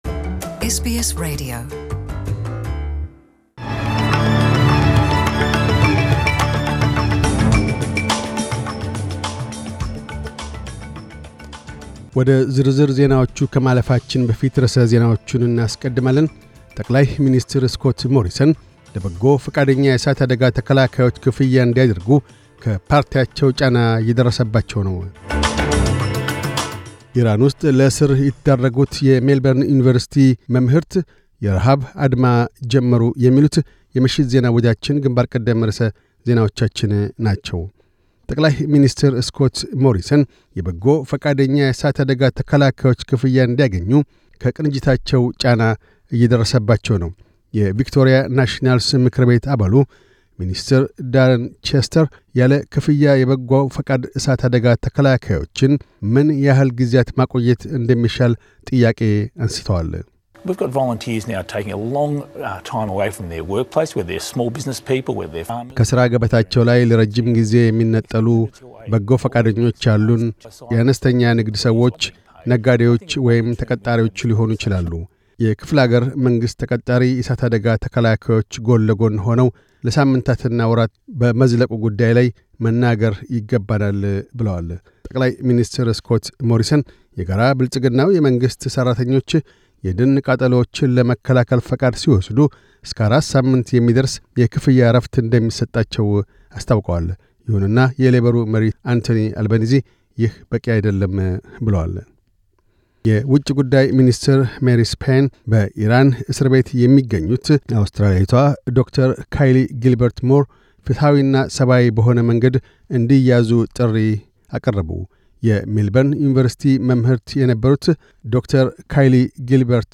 News Bulletin 2712